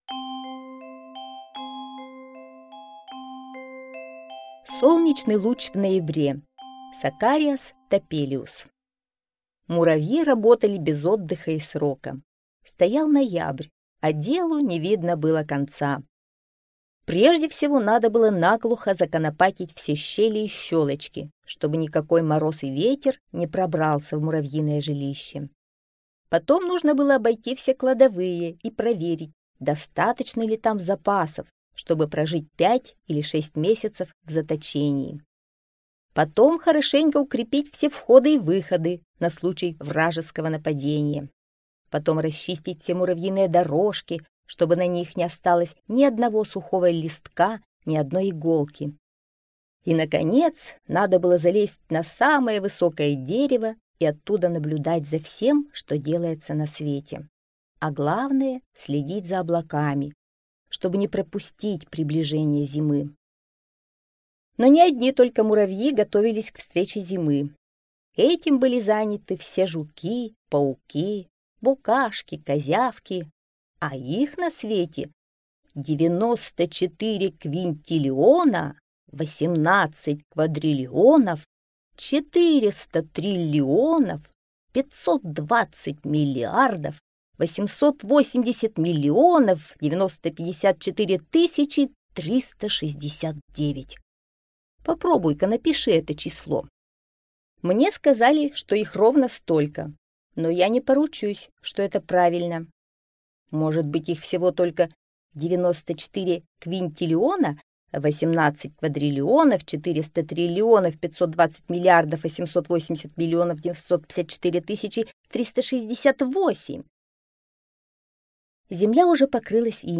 Солнечный луч в ноябре - аудиосказка Топелиуса С. Сказка о том, как в пасмурный ноябрьский день на землю пробрался Солнечный Луч.